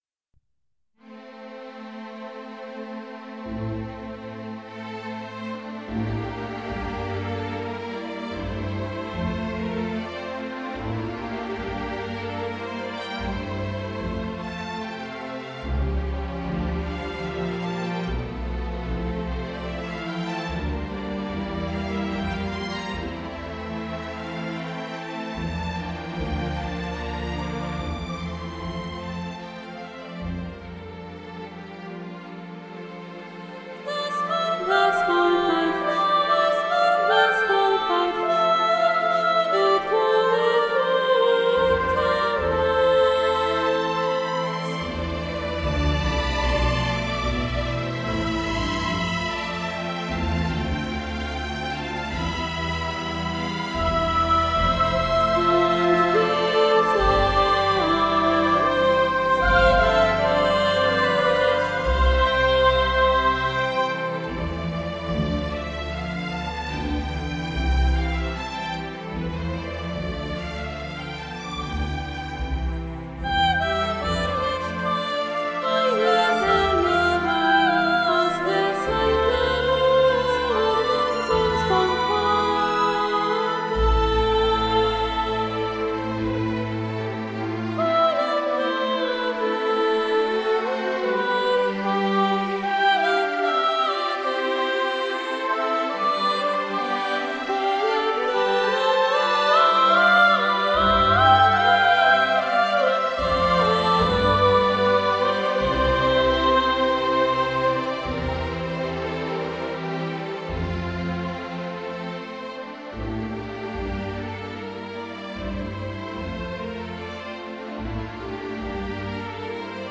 Das Wort ward Fleisch (2004) (Stilkopie Romantik) [1 Satz, ca. 6']
geistliches Konzert zur Weihnacht für zwei Stimmen (Sopran oder Tenor) und Orgel
Hörbeispiel (Klangdatei mit Sibelius Note Performer)